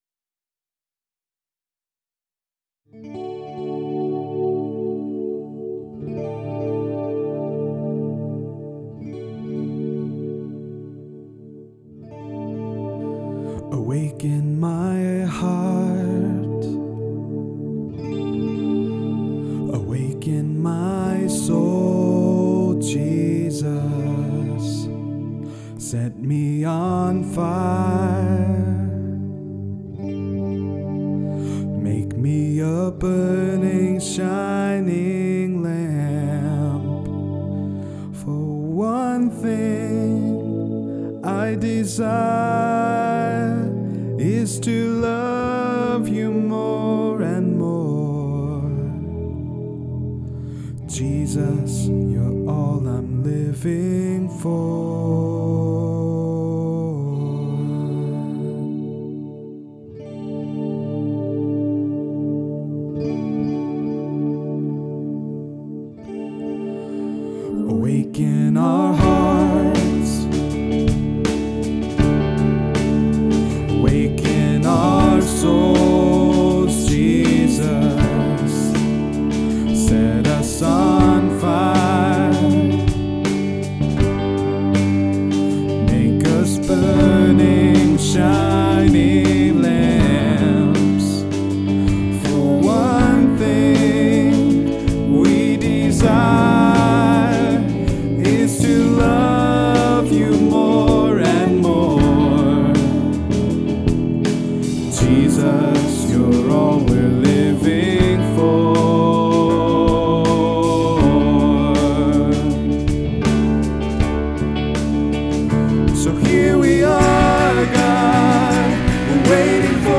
Music, Worship Leave a comment
We completed the first rough draft of “Onething” this weekend. The lyrics are based mainly from Psalm 27:4 but also echo several other Scripture verses.